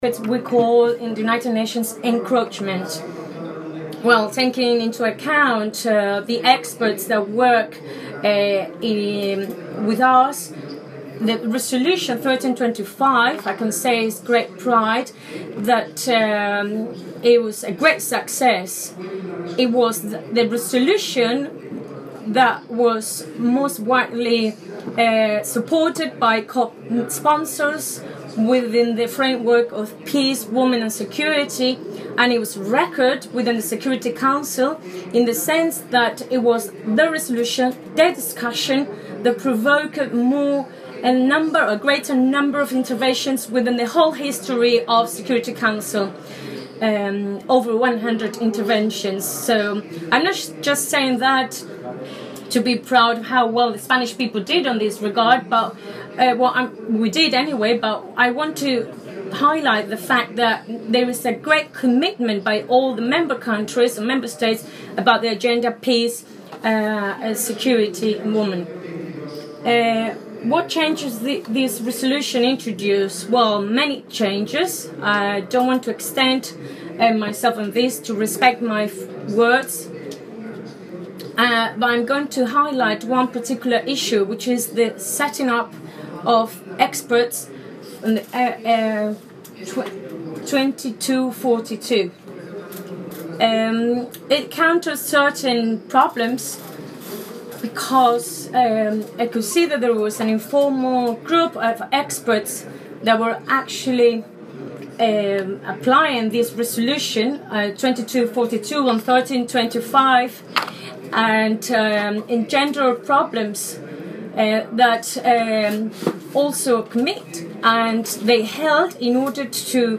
Ejemplos de interpretación en cabina:
Interpretación en cabina en Casa Mediterráneo Congreso: 1ª Conferencia Internacional sobre Diplomacia Preventiva en el Mediterráneo Alicante – mayo 2016